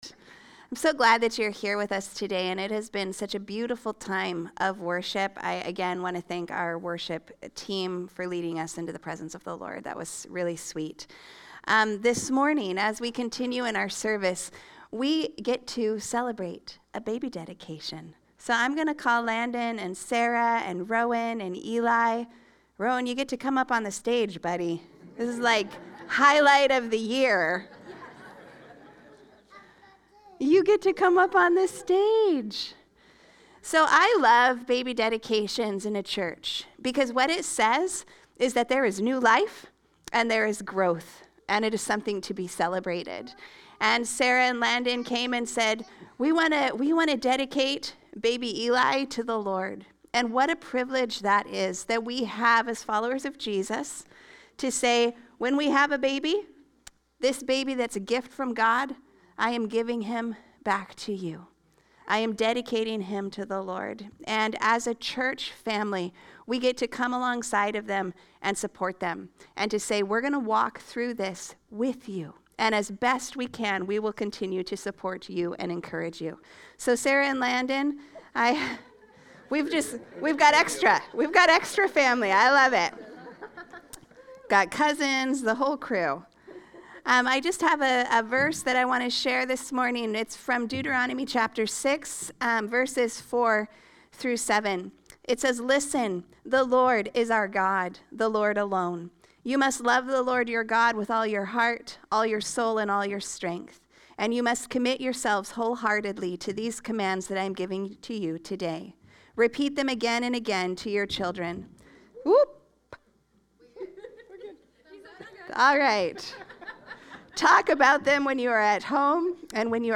Standalone Sermons